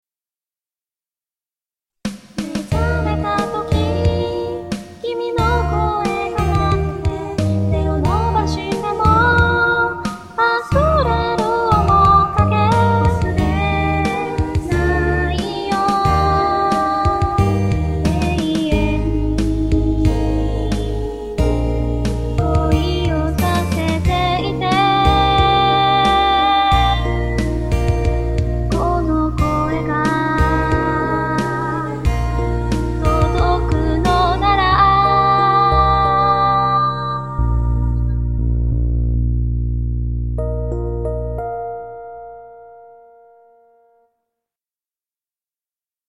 少年ぽい声だなと思いました。ちょっとつまる部分が泣いてるみたいでかわいい。